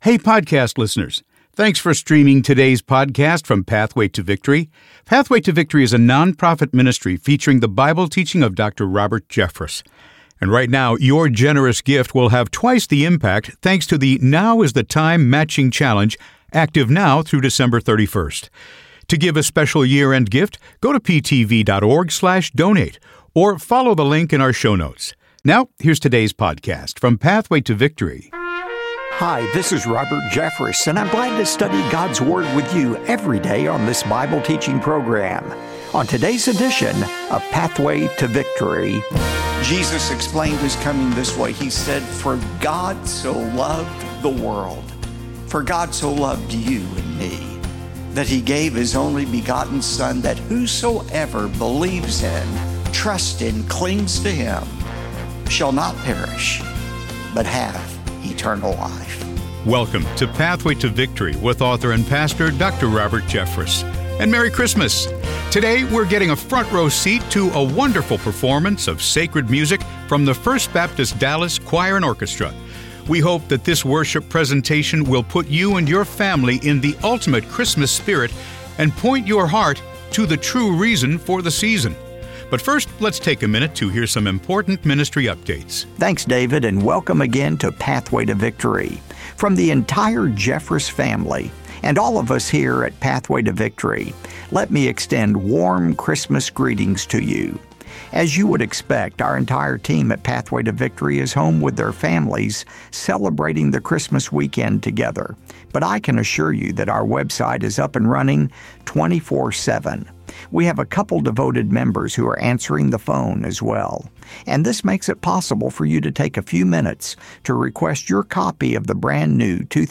Join us for a front row seat to a wonderful performance of sacred music from the First Baptist Dallas Choir and Orchestra.